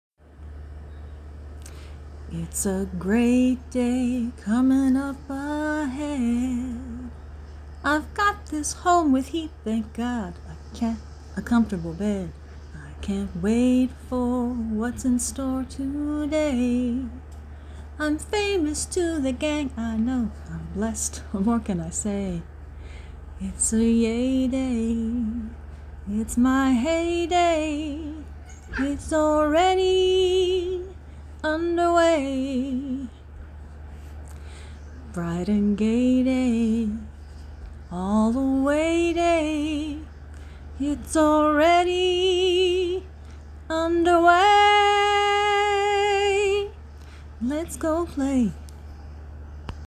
I love it when my cat decides to sing along with me, as he did during the recording of this song (
Click Here) that I start our day with, “Heyday”, also called “Squeaky’s Song.” Listen for him saying, “Yeah!” as I sing the word “Heyday”.